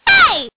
One of Princess Daisy's voice clips in Mario Kart DS